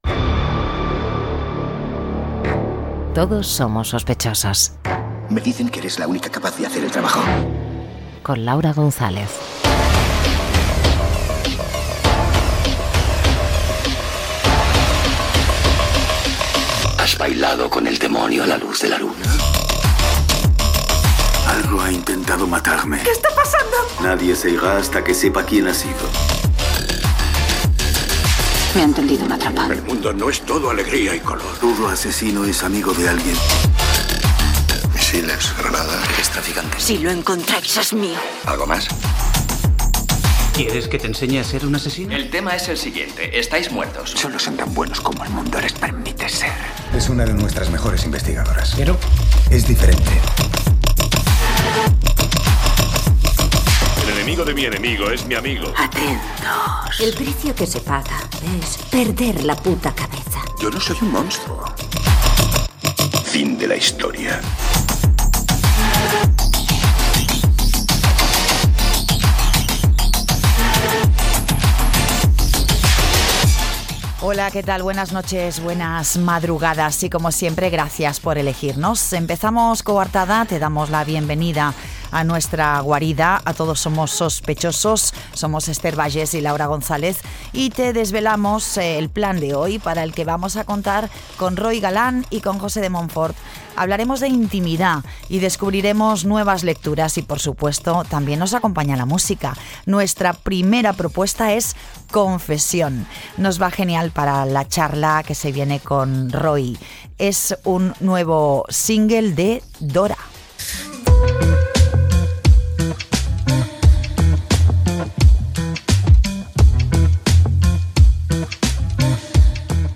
Careta del programa, presentació, sumari i tema musical
FM